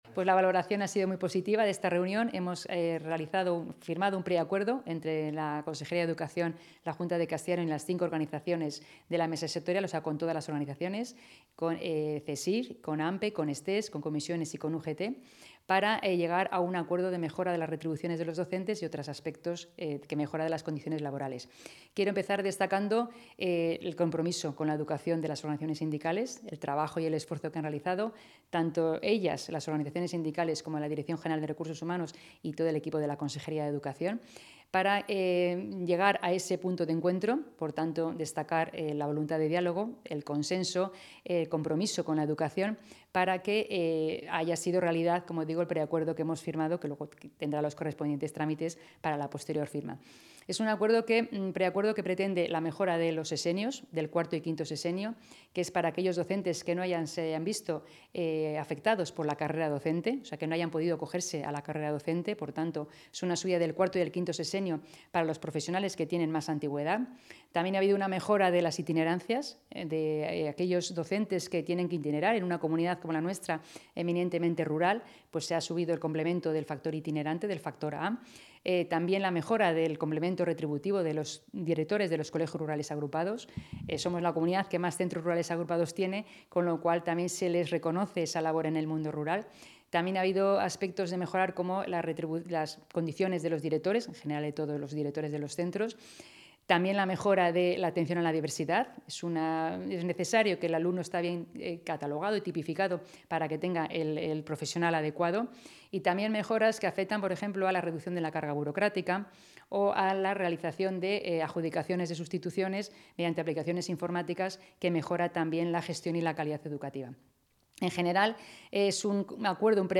Valoraciones de la consejera.